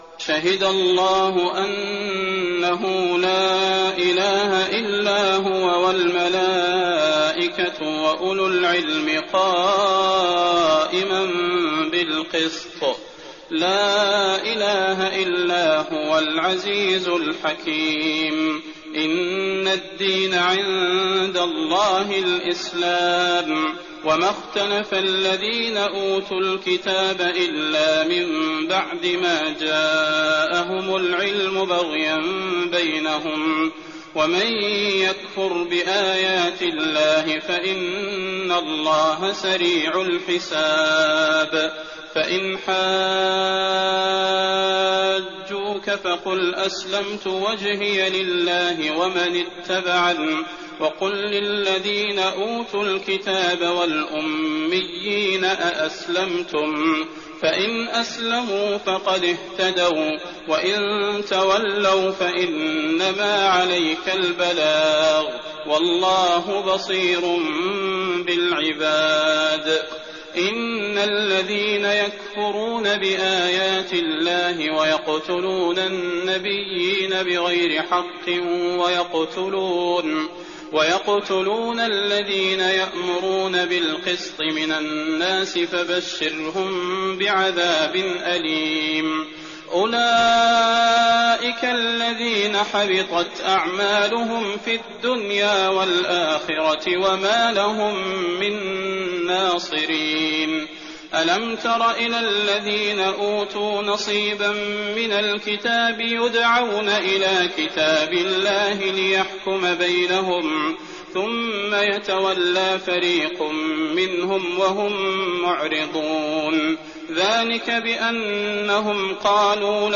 تراويح الليلة الثالثة رمضان 1419هـ من سورة آل عمران (18-92) Taraweeh 3rd night Ramadan 1419H from Surah Aal-i-Imraan > تراويح الحرم النبوي عام 1419 🕌 > التراويح - تلاوات الحرمين